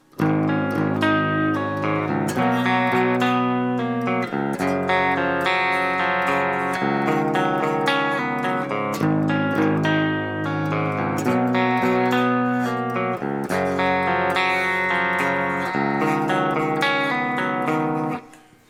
La mia chitarra non mi piace come suona, è troppo ristretta come dinamica e poco ariosa, a differenza della Martin del video in OP che però oltre a non essere mancina, non è mia.